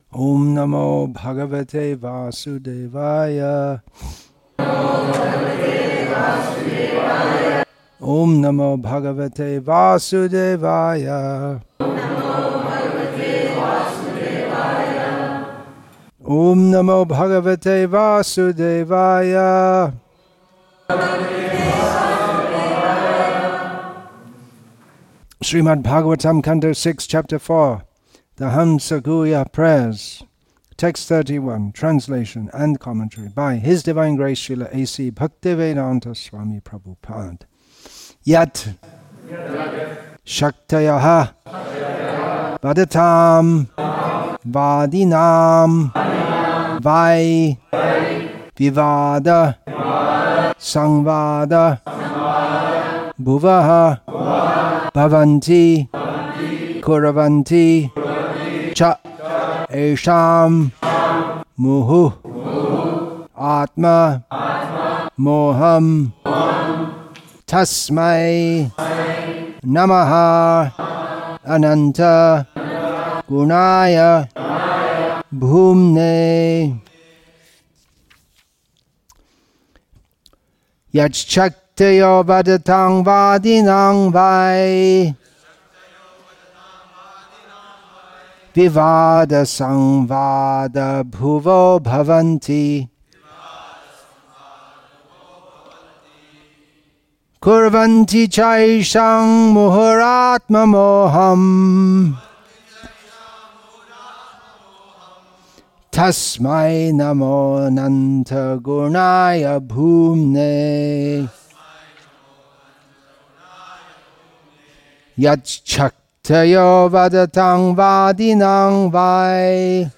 English with Polski (Polish) Translation; New Santipur, Russia , Poland Śrīmad-Bhāgavatam 6.4.31